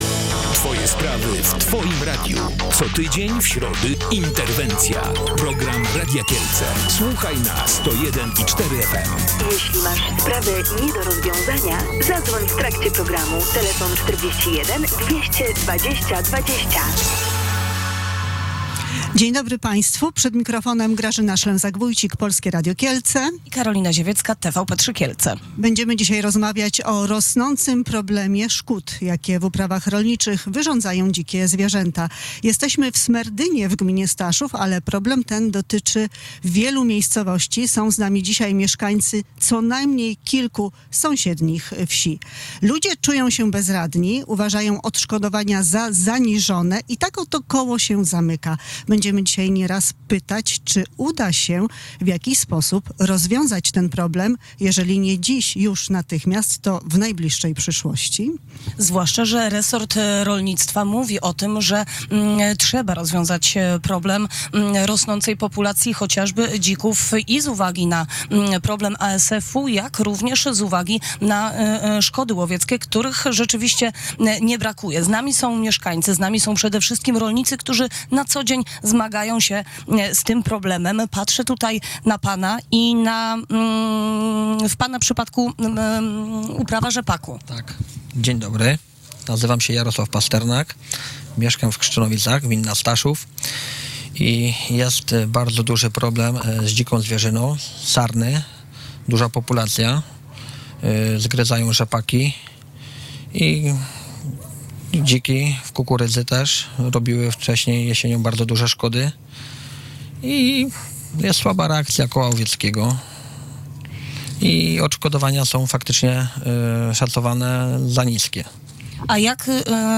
W środę (18 marca) w programie Interwencja mówili o tym w Smerdynie, w gminie Staszów mieszkańcy tej i kilku sąsiednich miejscowości.